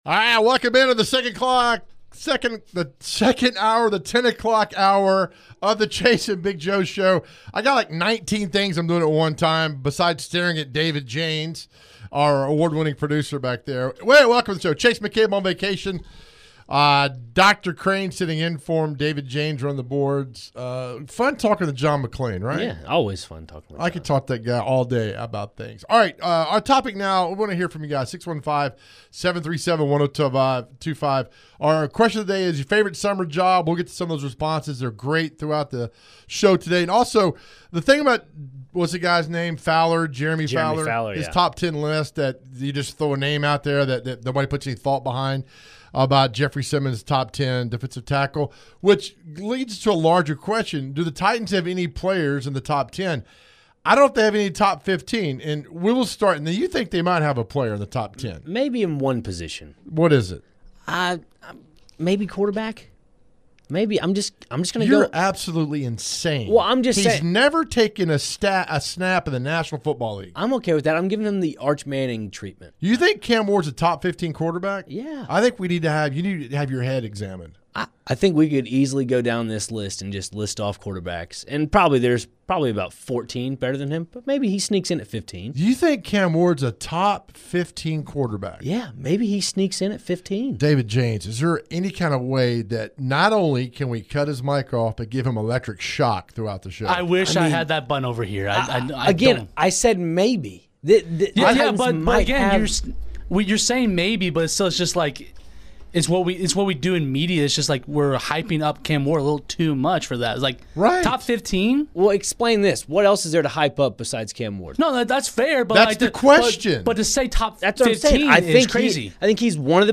They also took calls, they asked who are the most famous alumnus at all the SEC schools, and Celebrity Birthday